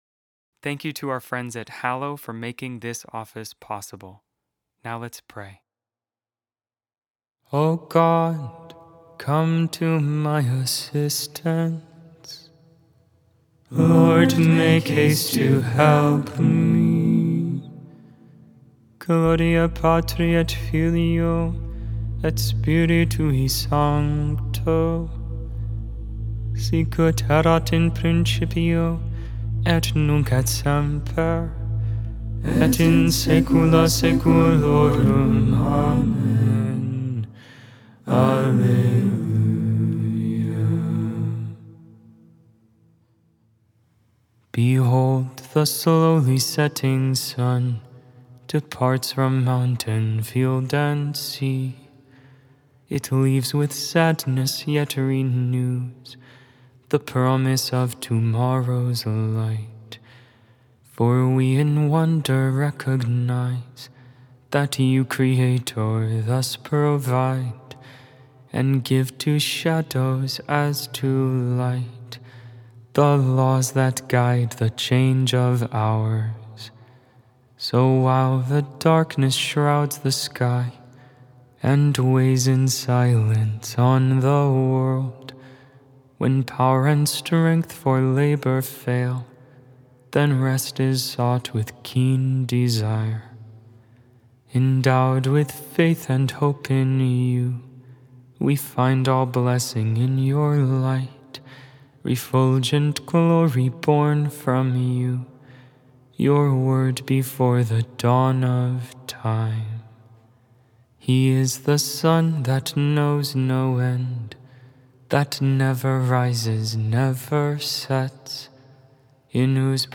Vespers, Evening prayer for the 32nd Wednesday in Ordinary Time, November 12, 2025.Memorial of St. Josephat, Bishop and Martyr Made without AI. 100% human vocals, 100% real prayer.